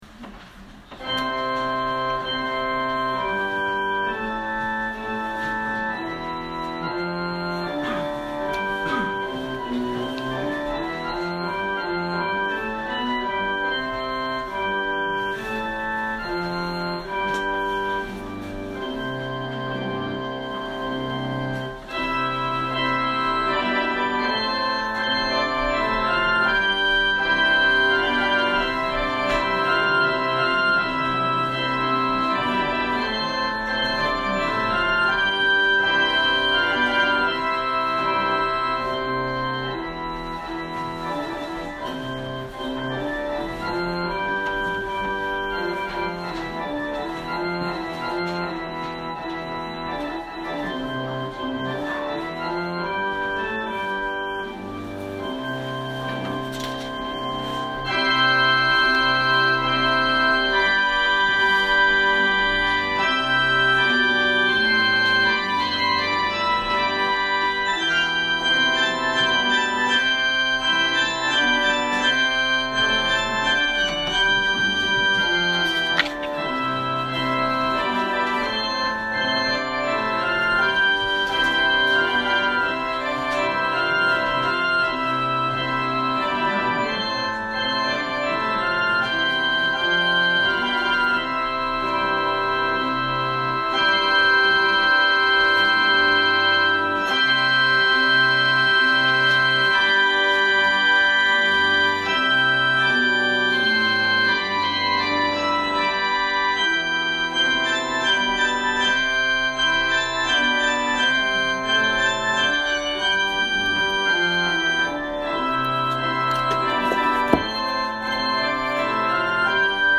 説教アーカイブ。
私たちは毎週日曜日10時20分から12時まで神様に祈りと感謝をささげる礼拝を開いています。
音声ファイル 礼拝説教を録音した音声ファイルを公開しています。